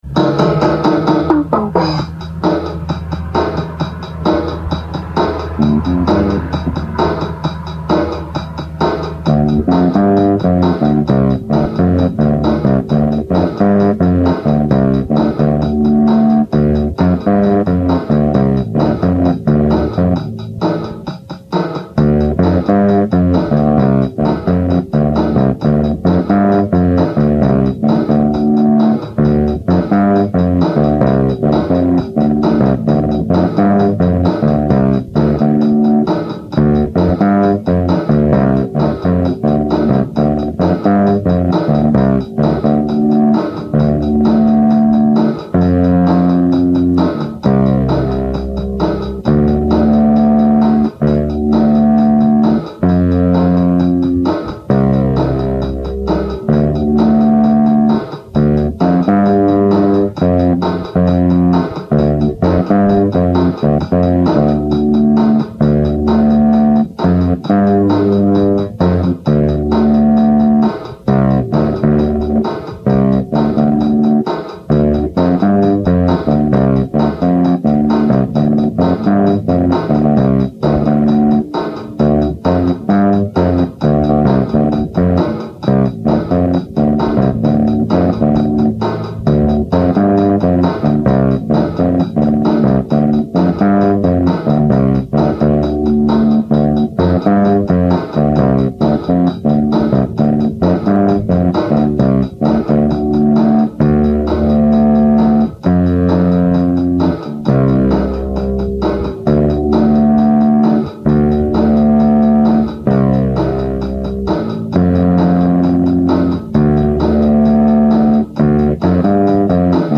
here’s me experimenting with my bass and a distortion pedal, along with a drum machine.
DistBass.mp3